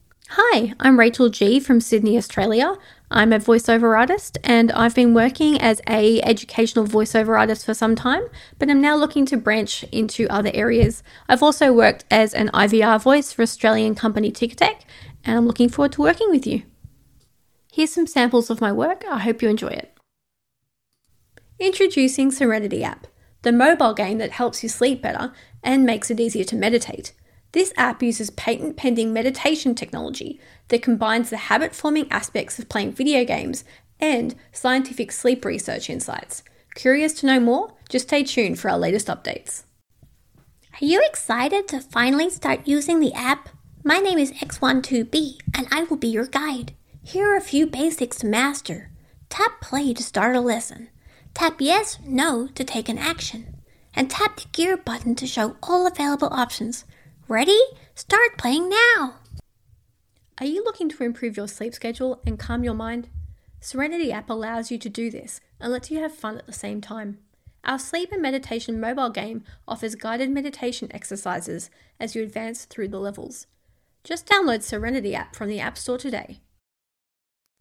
Hundreds of female voice talent.
My voice is versatile and adapts naturally to different needs: institutional, documentary, emotional and promotional.